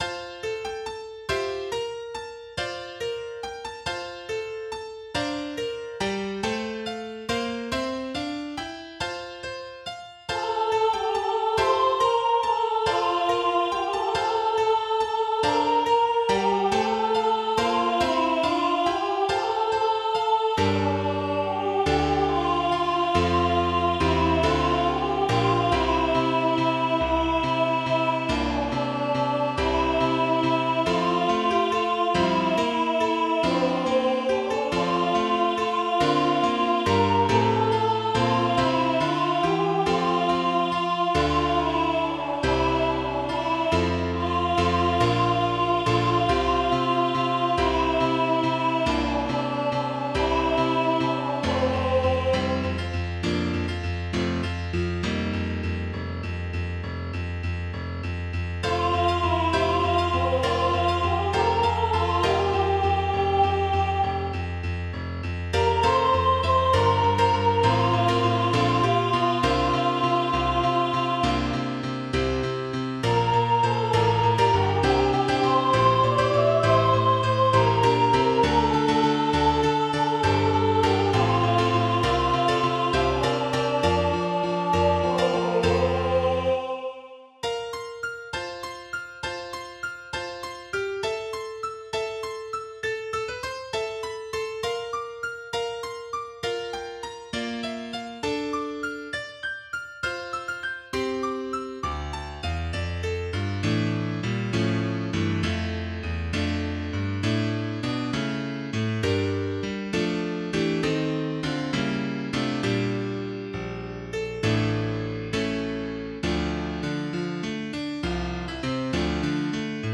12_Gloria_PatriSop2.mp3